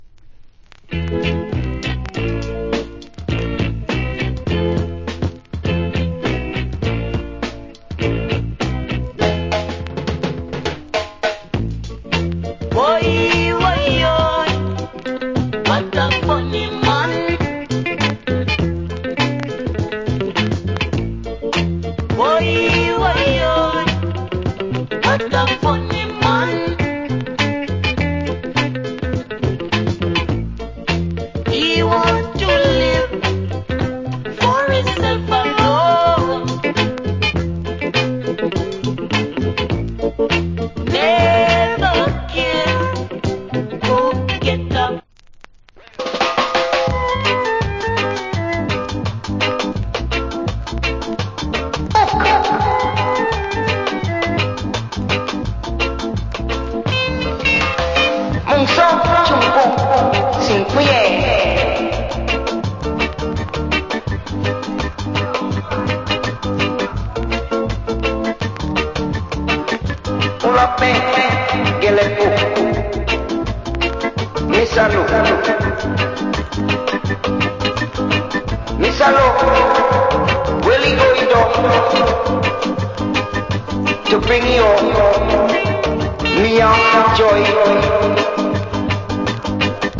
Nice Vocal.